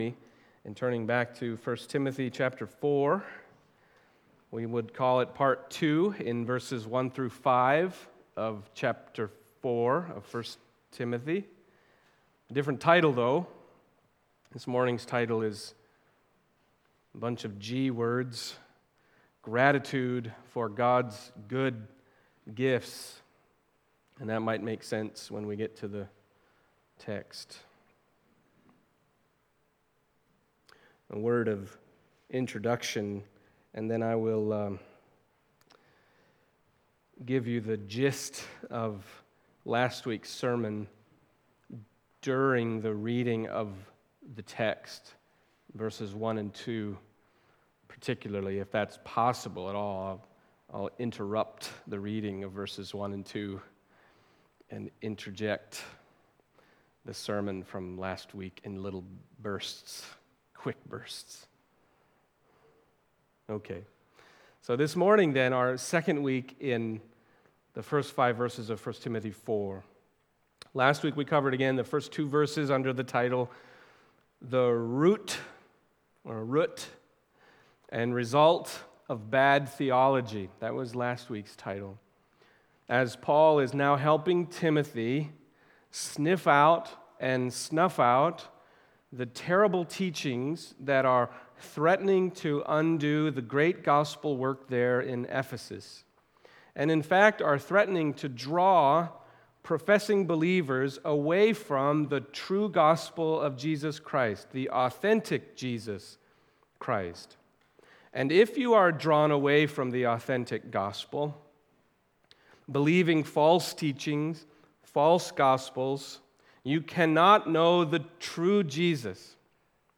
Passage: 1 Timothy 4:1-5 Service Type: Sunday Morning